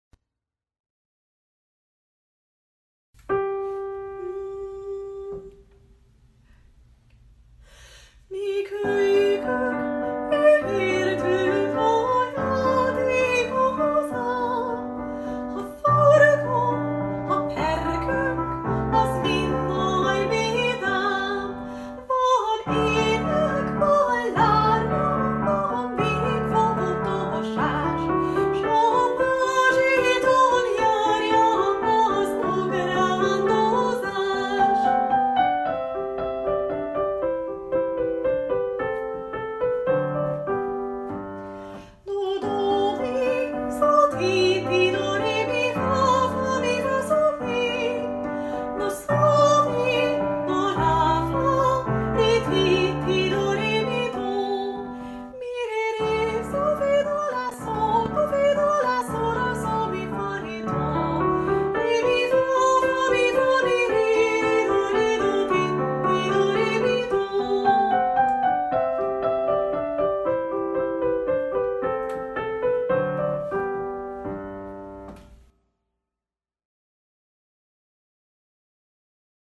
A változatosság kedvéért a felvételek otthon készültek. Vigyázat, nyomokban speciális effekteket (zajokat) tartalmazhatnak.